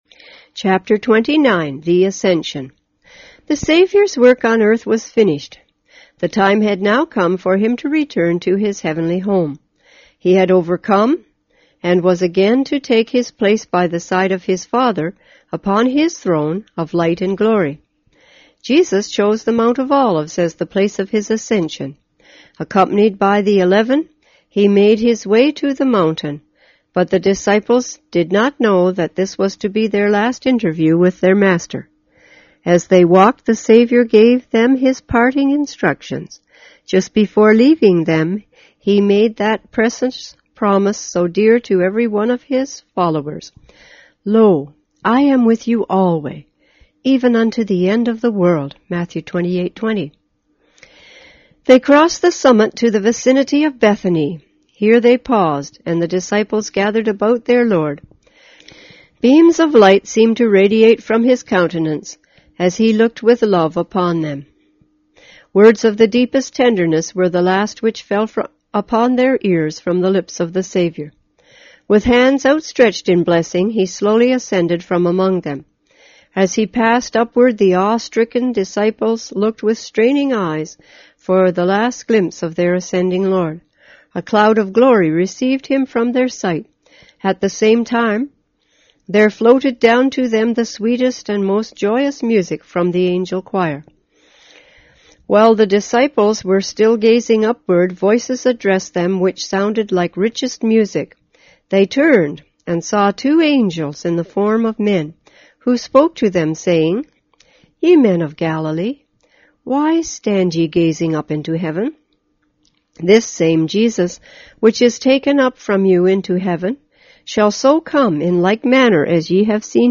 on 2010-06-03 - Ellen G. Whyte Books on Audio